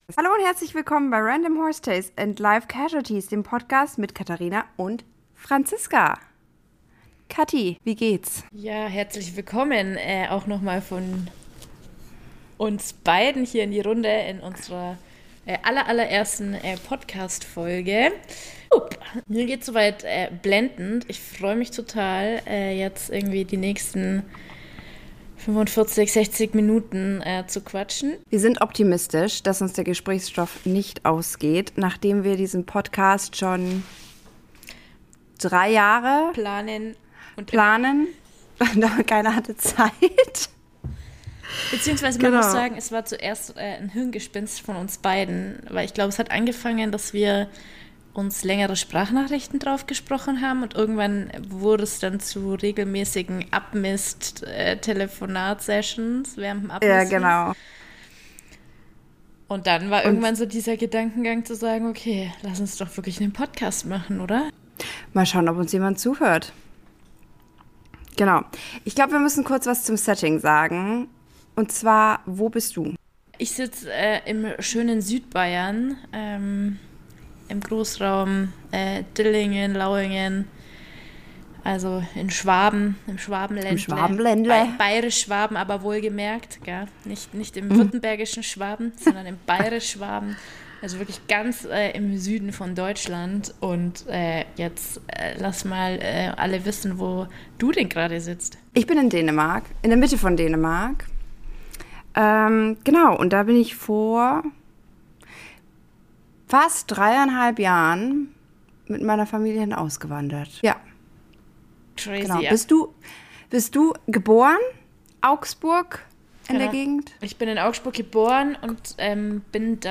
Uncut!